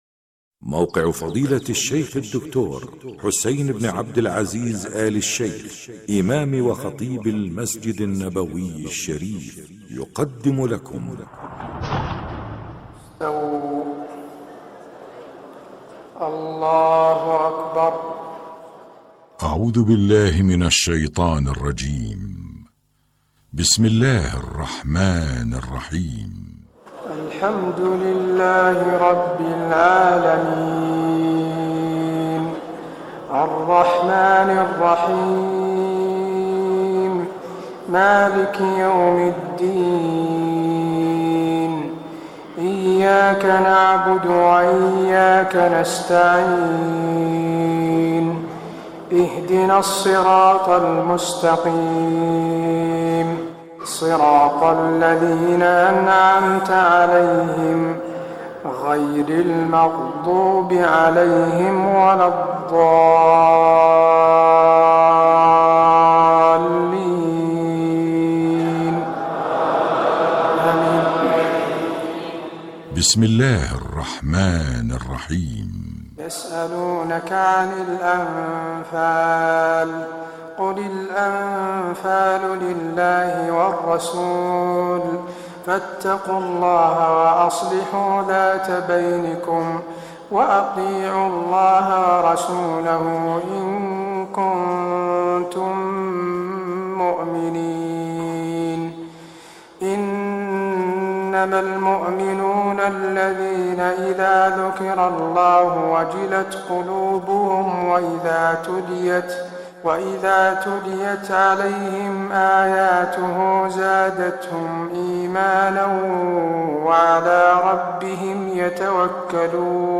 تهجد ليلة 28 رمضان 1425هـ من سورة الأنفال (1-40) Tahajjud 28 st night Ramadan 1425H from Surah Al-Anfal > تراويح الحرم النبوي عام 1425 🕌 > التراويح - تلاوات الحرمين